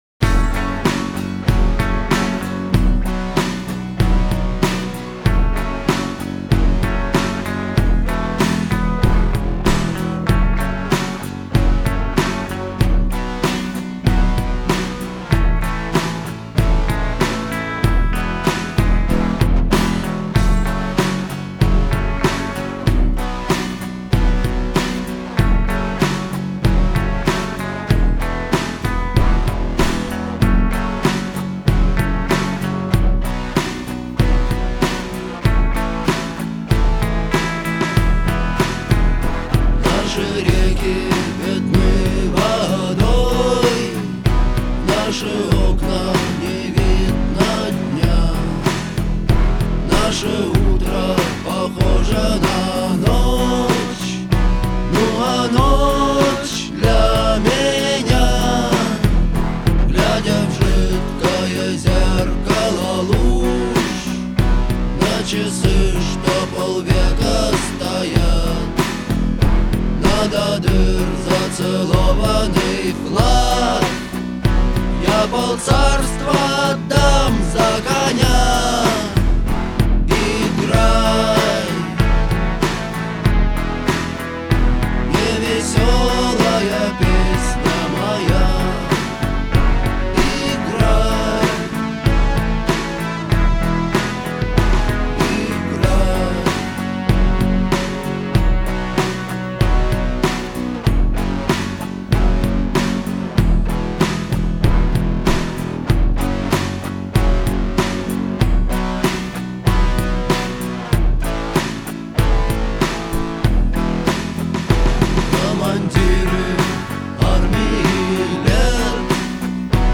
искренний голос